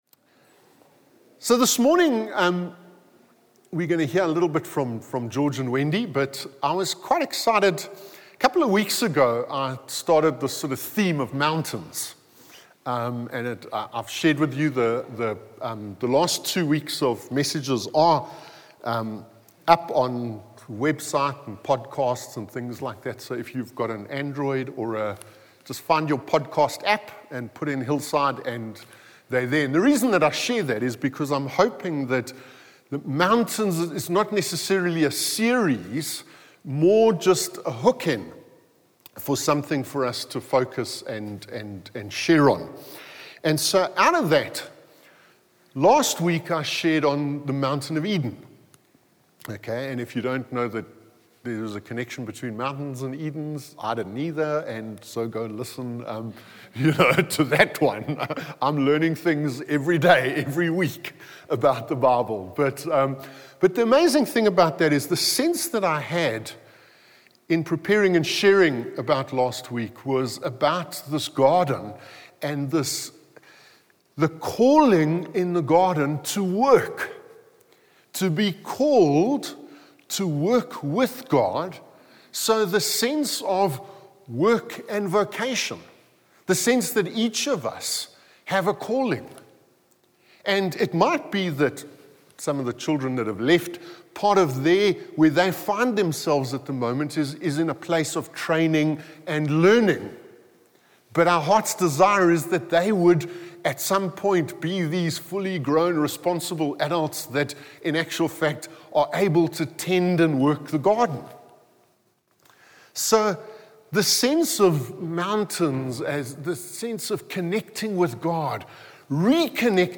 From Hillside Vineyard Christian Fellowship, at Aan-Die-Berg Gemeente.